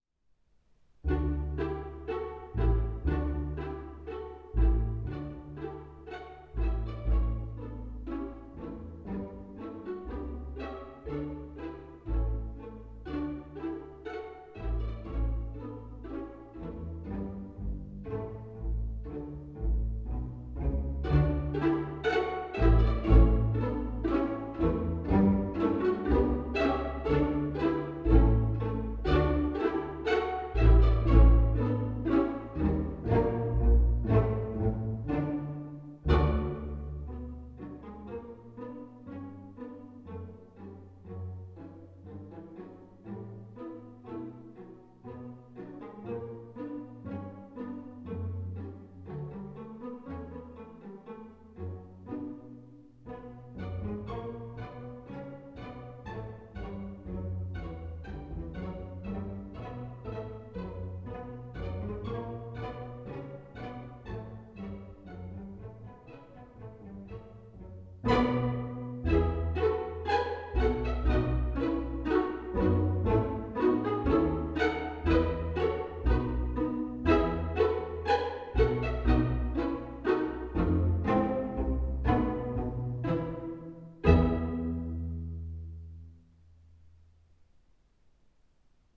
für Streichquintett